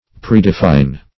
Predefine \Pre`de*fine\, v. t. To define beforehand.